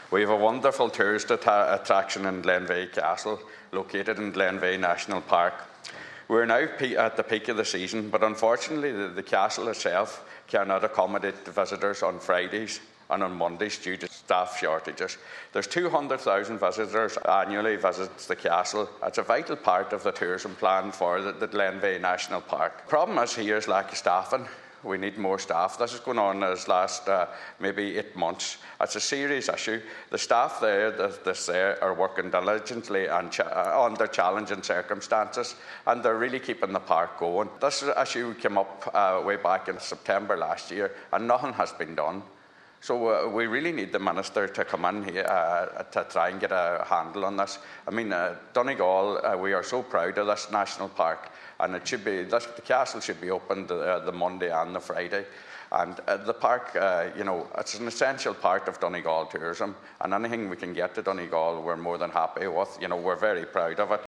Speaking in the Seanad earlier this week, Senator Boyle urged the Minister for Tourism to get a “handle” on the situation: